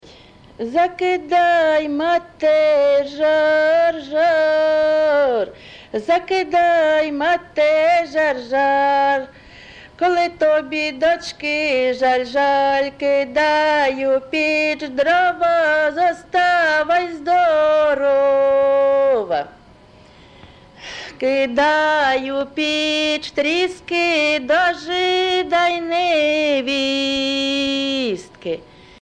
ЖанрВесільні